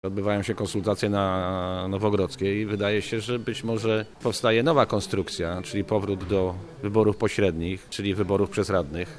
Waldy Dzikowski z Platformy Obywatelskiej wyraził obawy, że ma to związek z zmianami przygotowywanymi przez PiS, dotyczącymi sposobu wyborów władz miast i gmin.